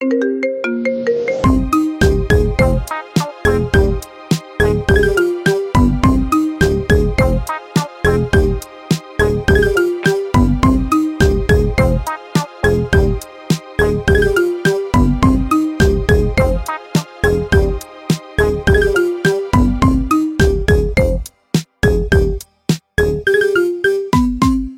Kategori Marimba Remix